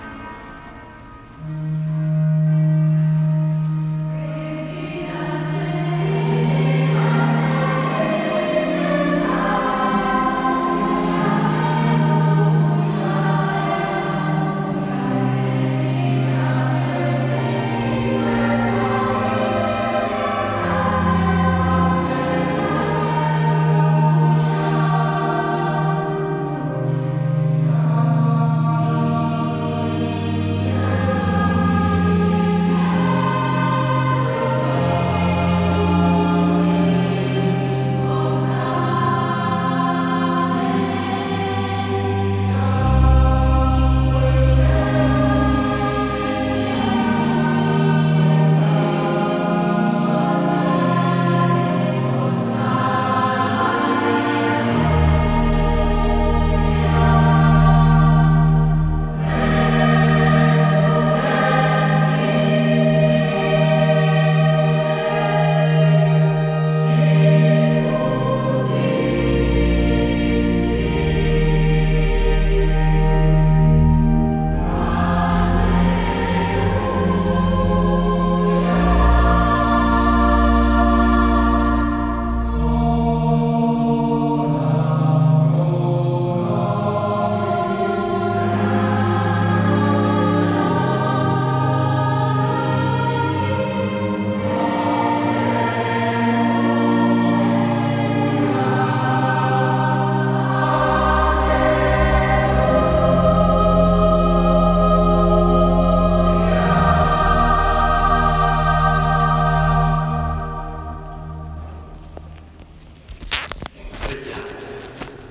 Settimana Santa in immagini e suoni
Molto infatti dobbiamo alla corale degli adulti e al coretto dei bambini.